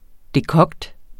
Udtale [ deˈkʌgd ]